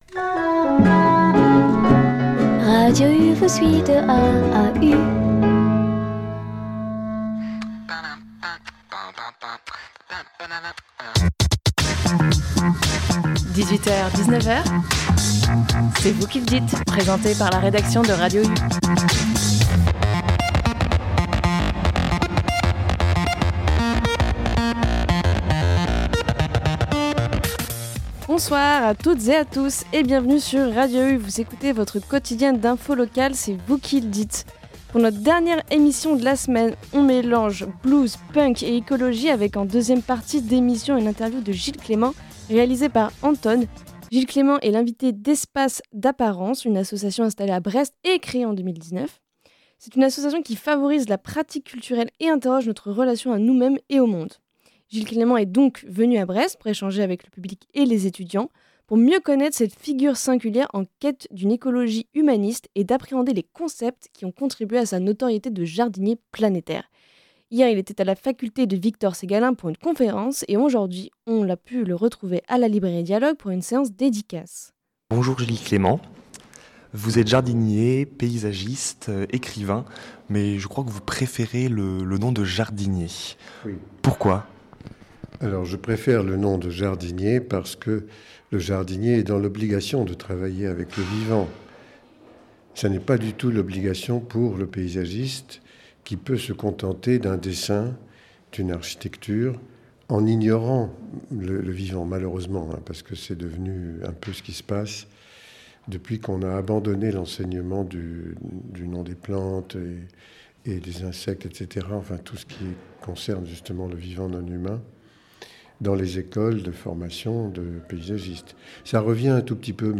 Interview de Gilles Clément sur Radio U dans le cadre de l'évènement [Gilles Clément à Brest] organisé par Espace d'apparence, le 5/12/2025.